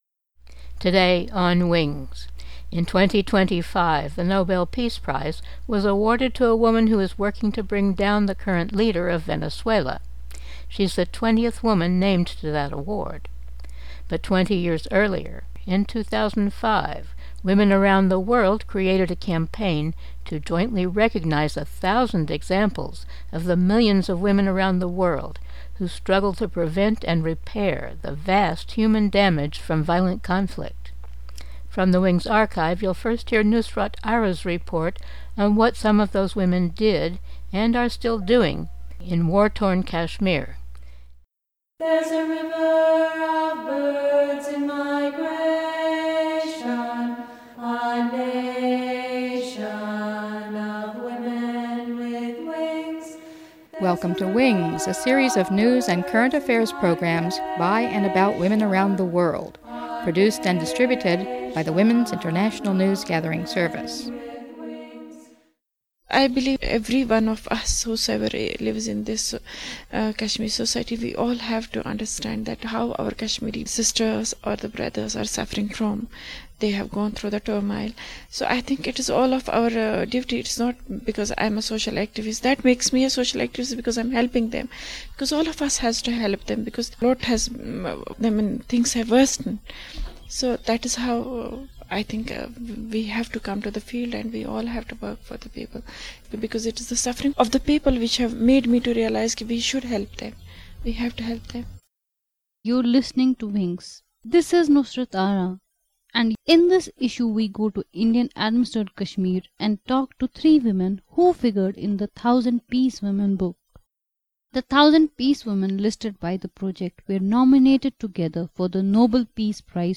village widows